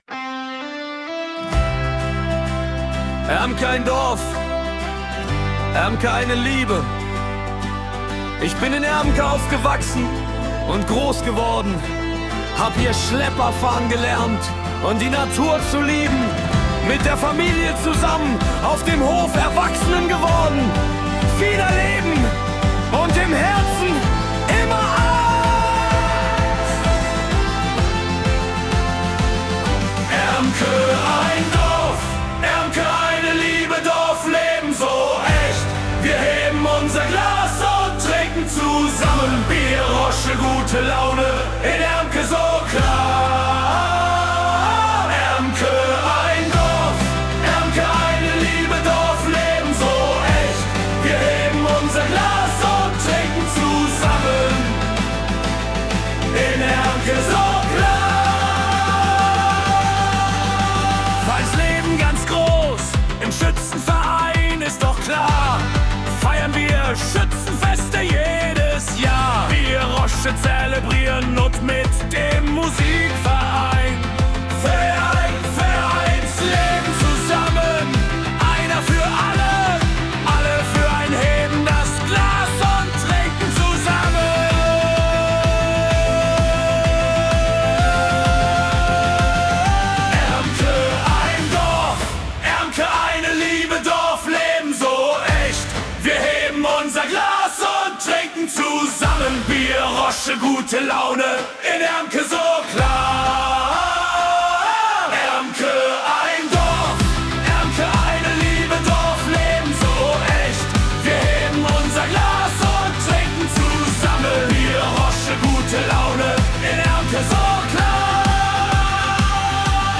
Dauer: ca. 4:00 Min • KI-generiert mit viel guter Laune